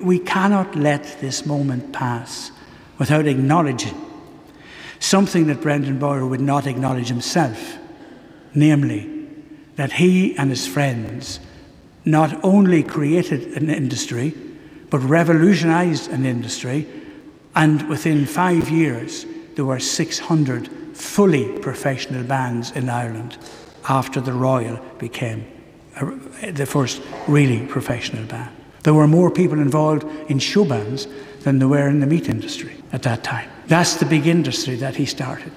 During the funeral, Fr D’arcy touched on how Brendan and his friends revolutionised the music industry.